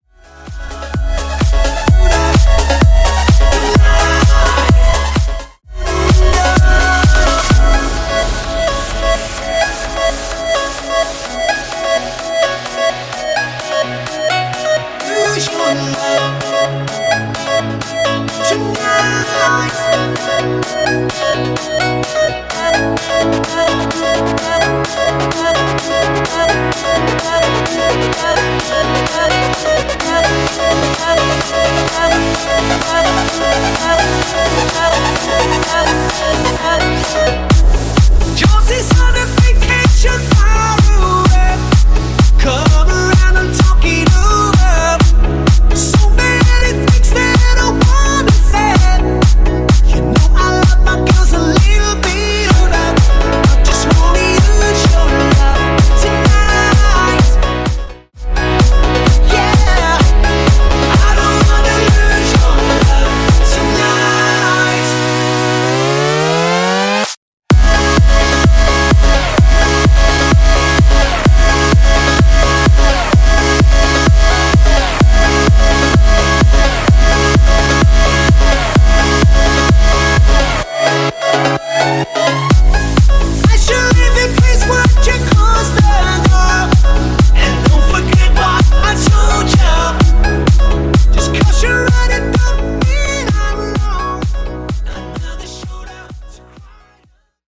Genres: 80's , RE-DRUM , ROCK Version: Clean BPM: 126 Time